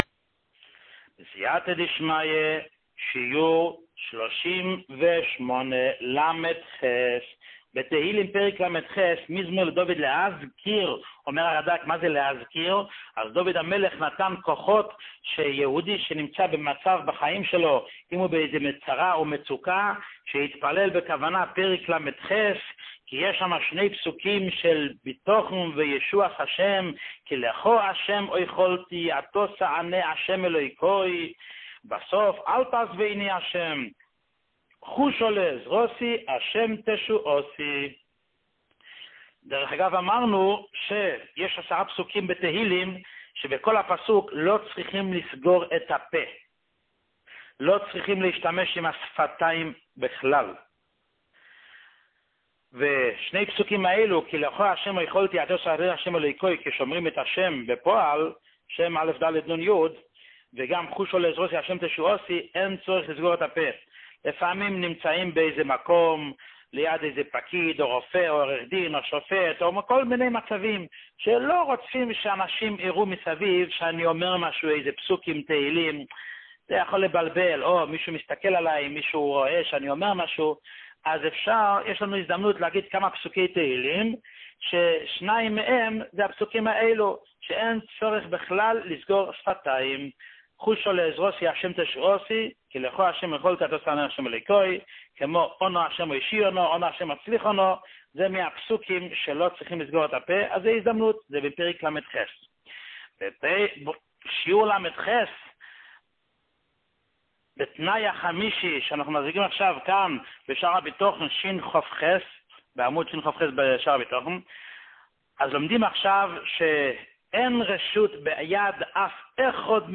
שיעורים מיוחדים
שיעור 38